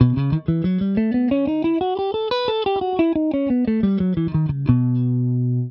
Tonique M2 M3 P4 P5 b6 b7 Tonique
Exemple audio " Si Mixolydien b13 "
Gamme guitare Jerrock
5-Si_Mixolydien_b13.wav